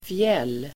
Uttal: [fjel:]